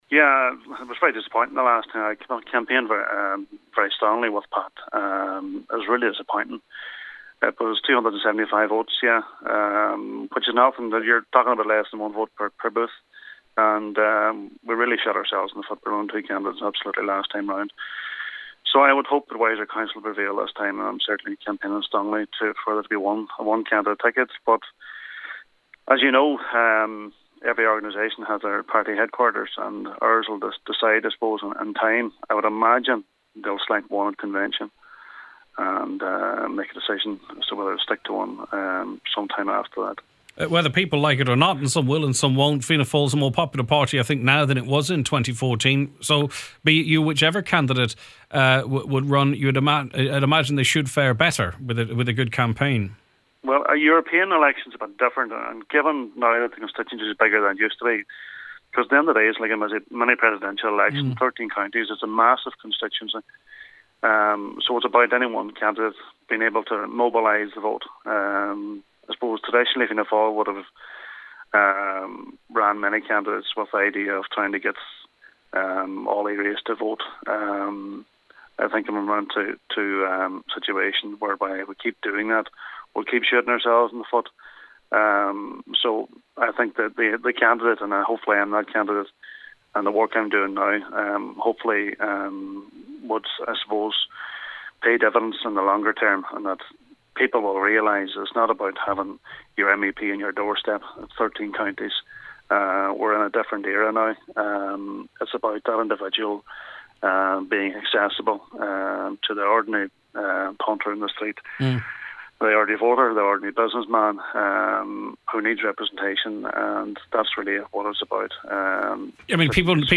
Speaking on the Nine Till Noon Show, Blaney is confident that he will have a successful campaign: